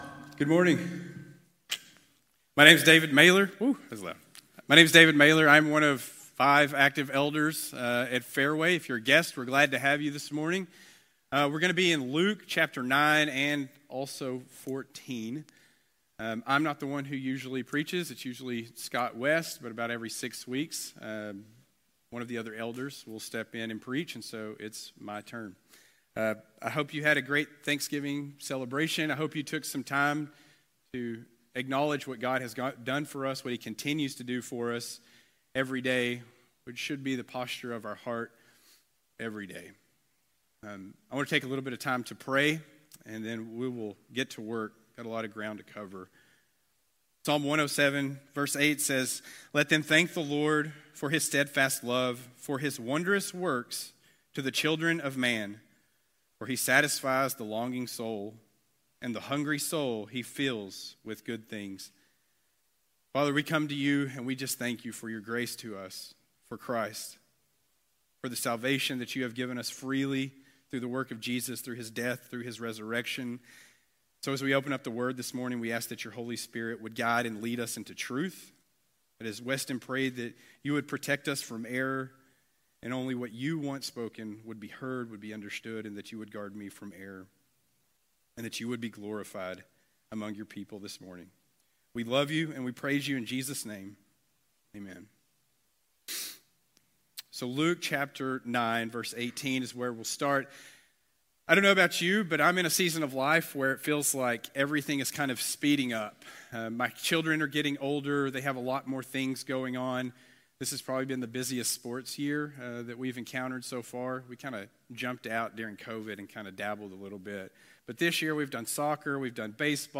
Sermons | Fairway Baptist Church